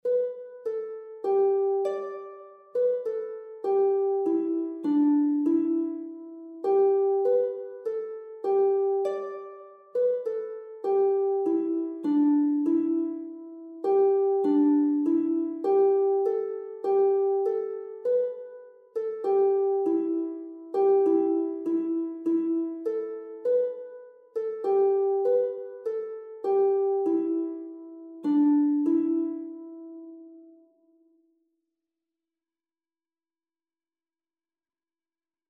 Een wiegeliedje
dit liedje is pentatonisch